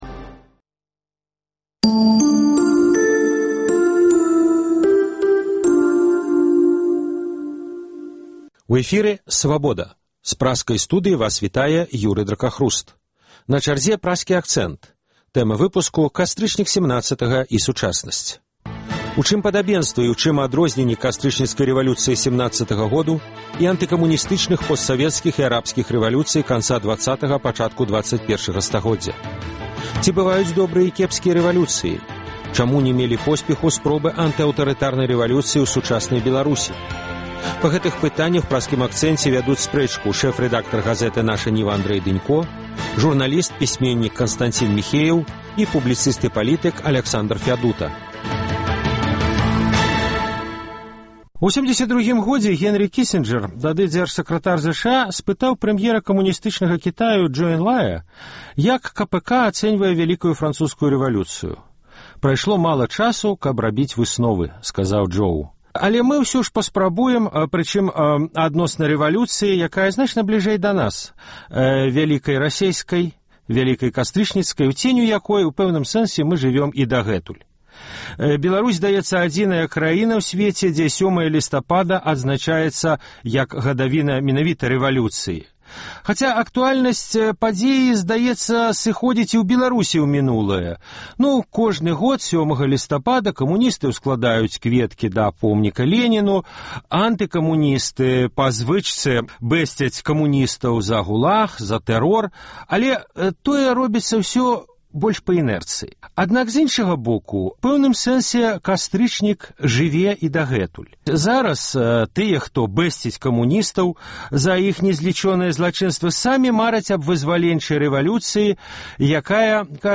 Чаму ня мелі посьпеху спробы антыаўтарытарнай рэвалюцыі ў сучаснай Беларусі? На гэтую тэму вядуць спрэчку